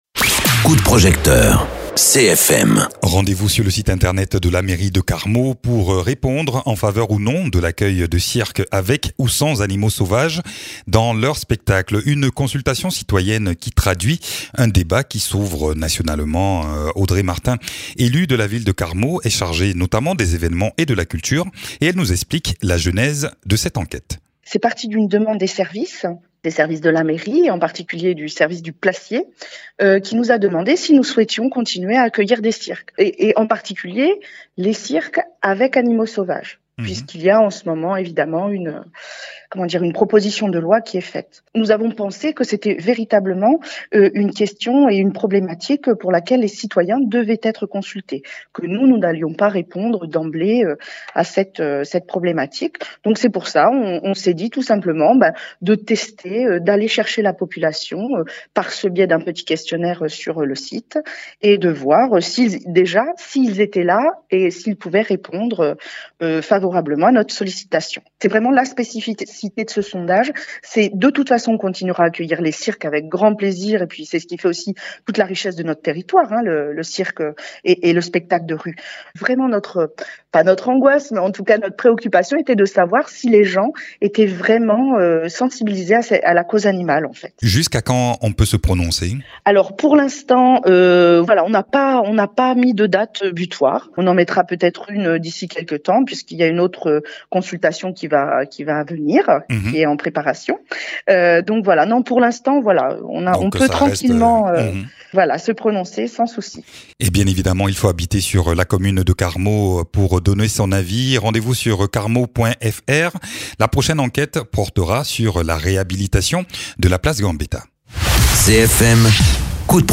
Interviews
Invité(s) : Audrey Martin, adjointe de la mairie de Carmaux aux événements, culture, jeunesse et jumelage